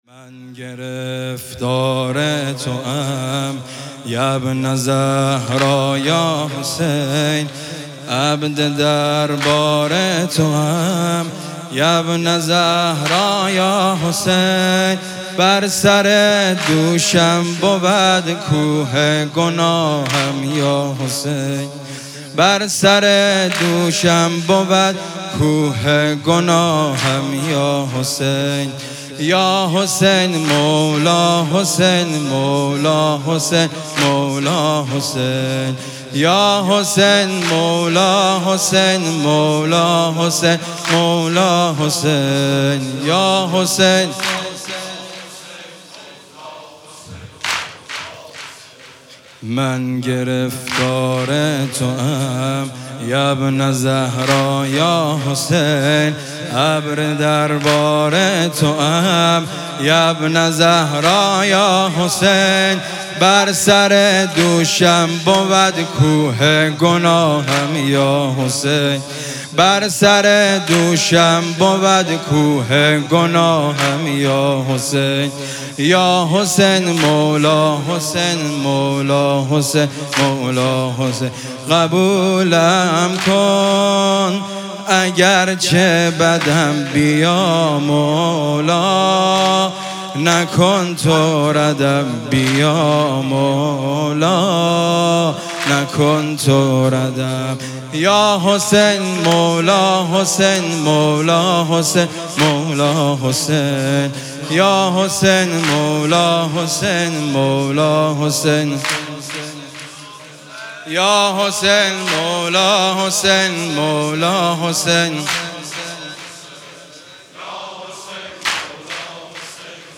گلچین مداحی ویژه شب چهارم محرم ۹۷